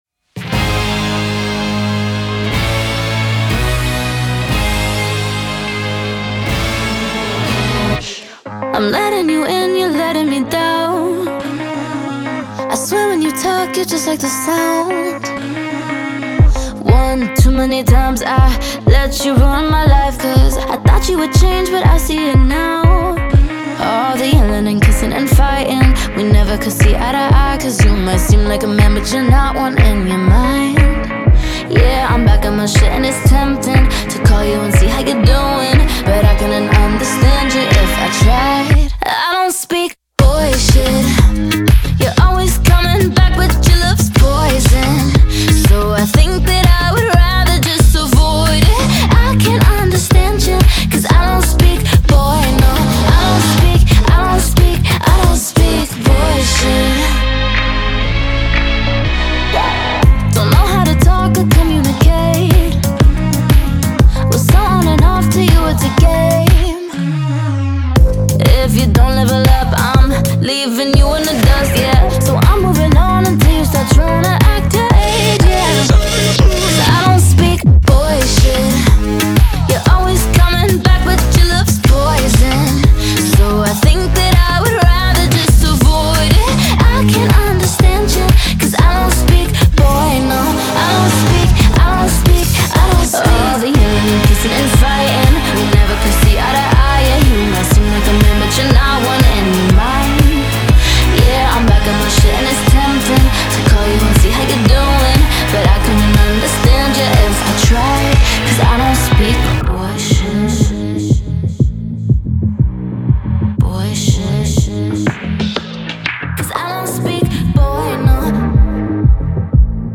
BPM121-121
Pop song for StepMania, ITGmania, Project Outfox
Full Length Song (not arcade length cut)